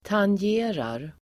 Ladda ner uttalet
Uttal: [tanj'e:rar el. tangge:rar]
tangerar.mp3